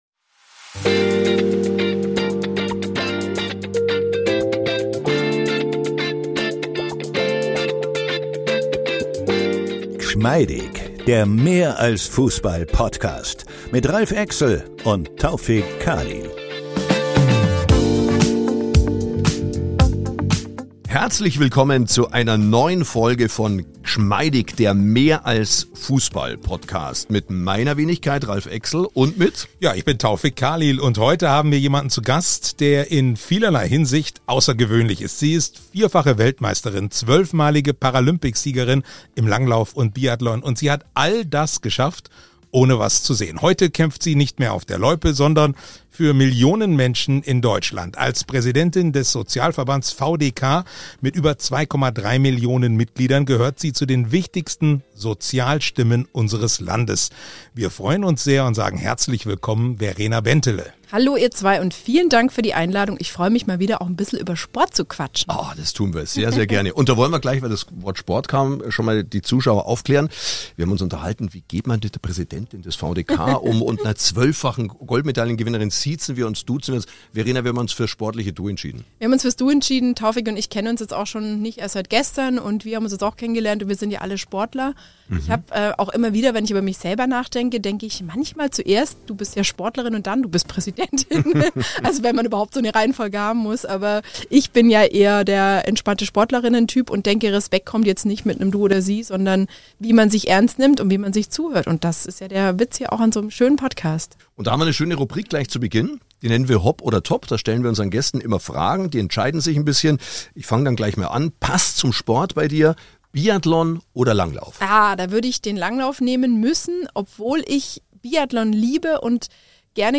Diese Fragen - und viele mehr beantwortet Verena Bentele in einem lockern Gespräch in Gschmeidig - der Mehr-als-Fußball-Podcast.